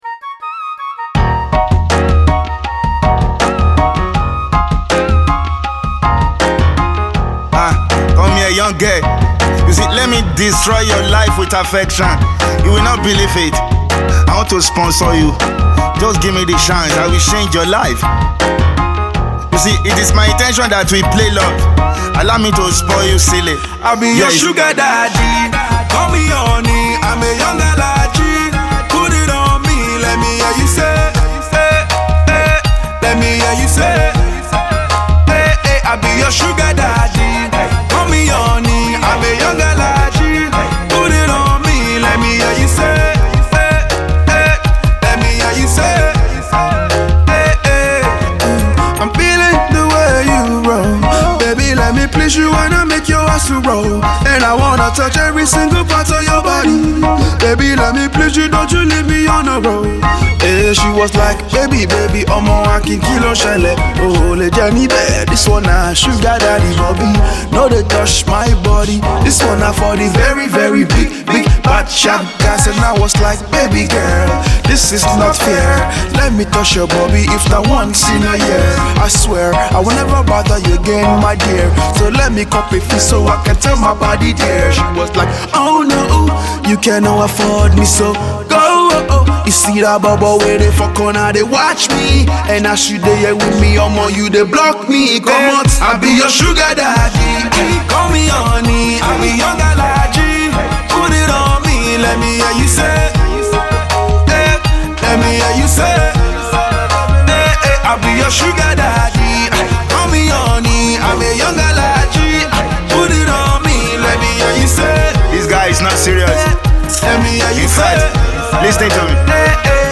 a smooth R&B song